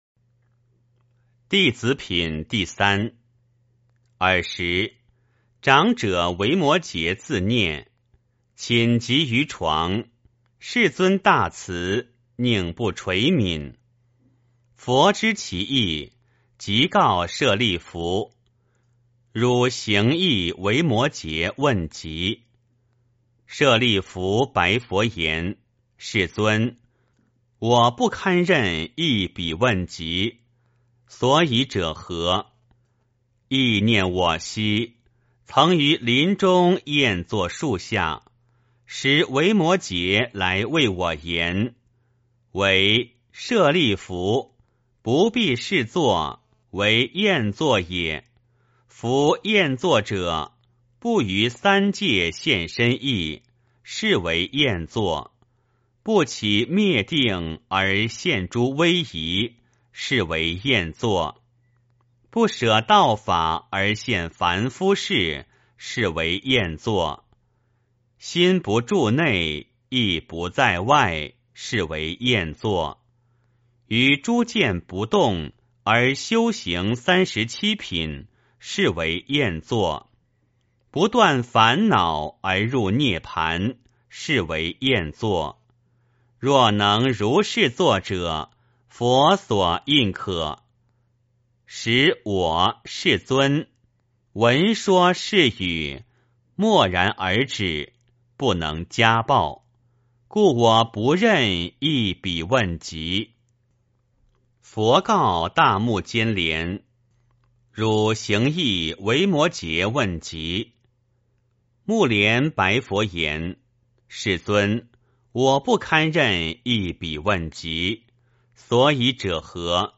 维摩诘经-弟子品第三 - 诵经 - 云佛论坛